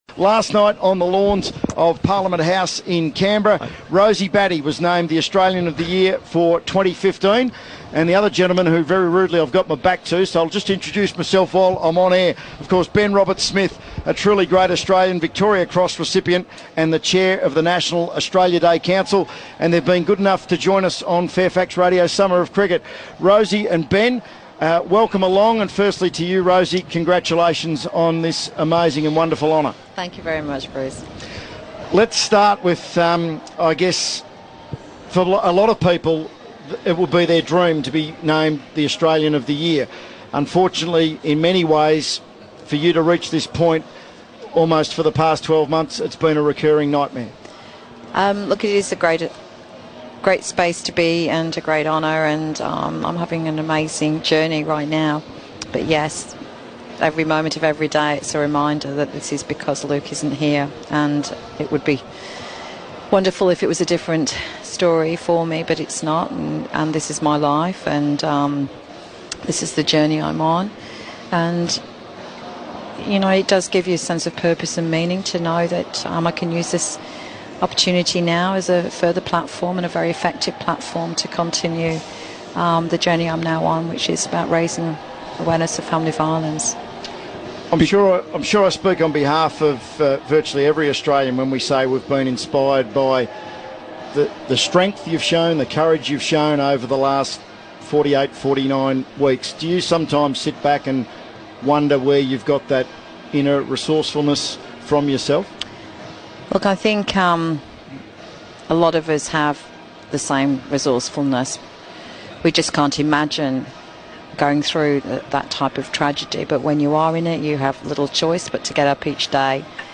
We were lucky enough to have two very special Australians in the commentary box during Monday's rain delay: Australian of the Year Rosie Batty, and Victoria Cross recipient Ben Roberts-Smith, who is chair of the National Australia Day Council.